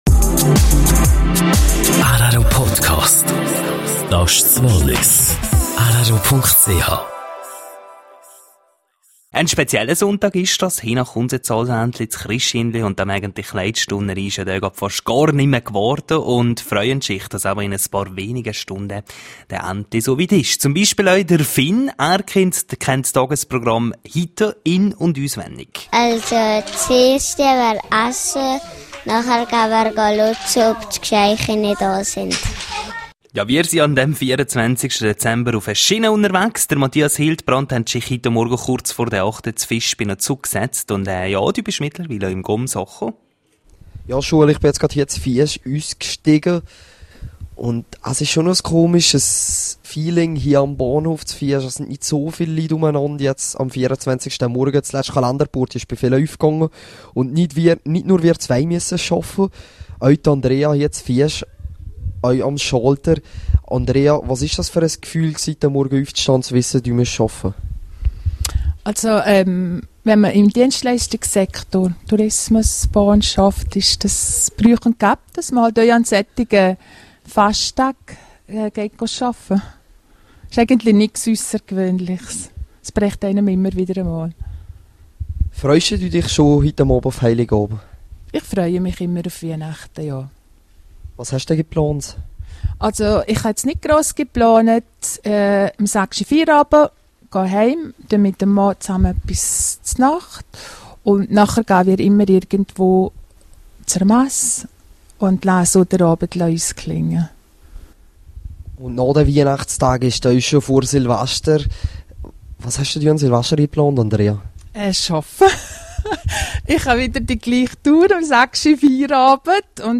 rro unterwegs im Zug: Interview